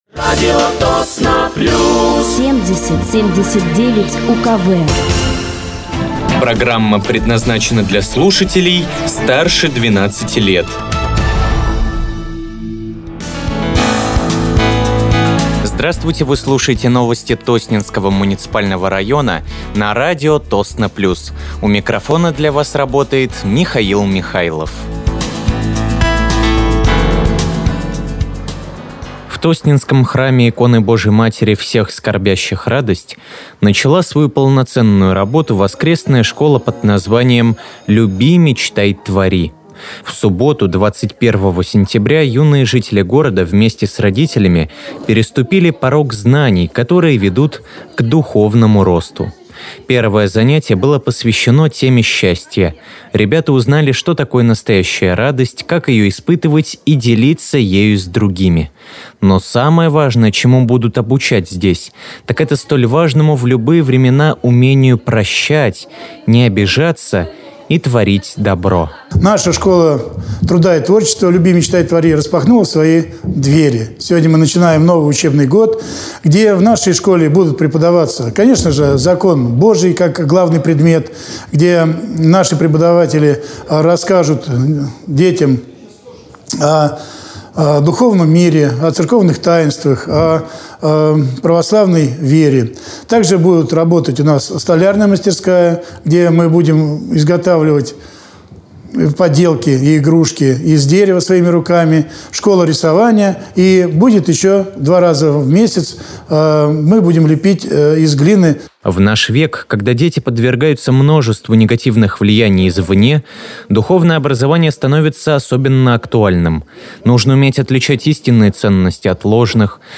Выпуск новостей Тосненского муниципального района от 25.09.2025
Вы слушаете новости Тосненского муниципального района на радиоканале «Радио Тосно плюс».